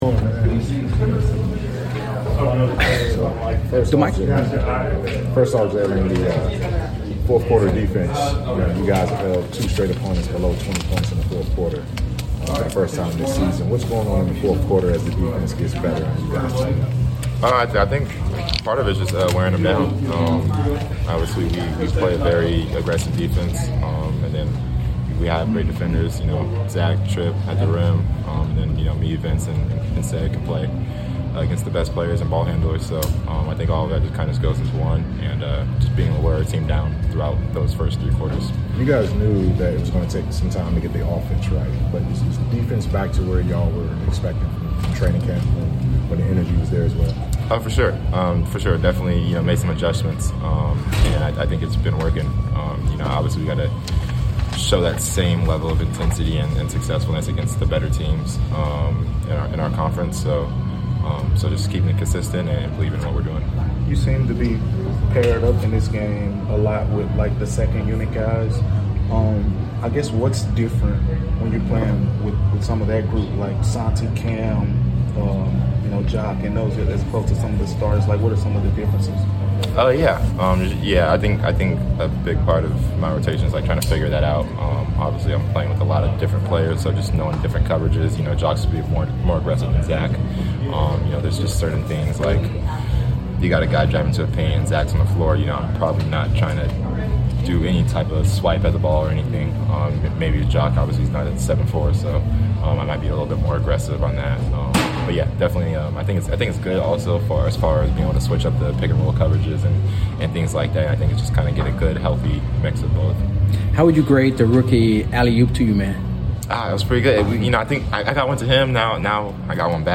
Memphis Grizzlies Guard Jaylen Wells Postgame Interview after defeating the Portland Trail Blazers at FedExForum.